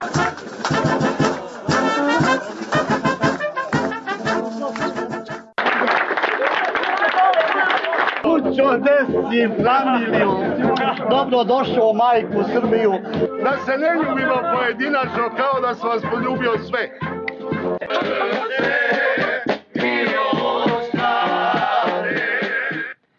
Javnost u Srbiji i regionu je u četvrtak i petak gledala snimke vašarskog dočeka haškog optuženika generala Dragoljuba Ojdanića na beogradskom aerodromu, nakon što je odslužio dve trećine kazne od 15 godina zatvora, sa sve trubačima praćenih razdraganim Užičanima sa uzdignutim rukama, uz uzajamne razmene sočnih poljubaca.
Atmosfera